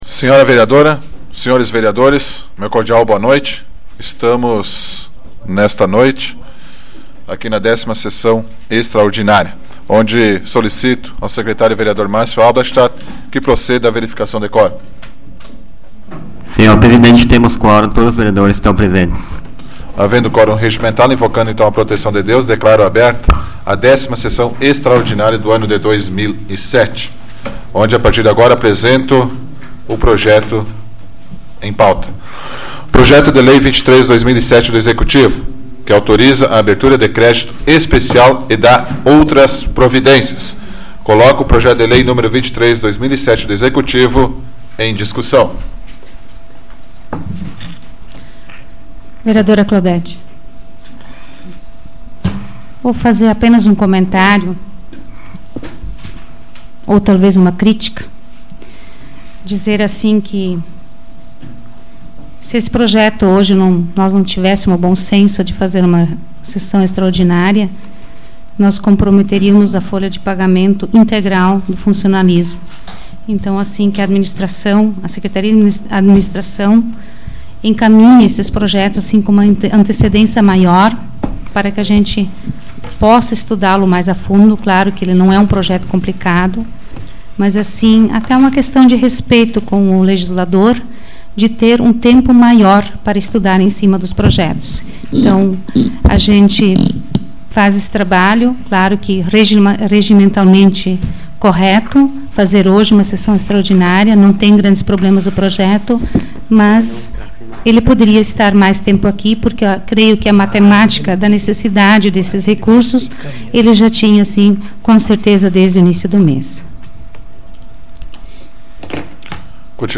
Áudio da 95ª Sessão Plenária Ordinária da 12ª Legislatura, de 27 de agosto de 2007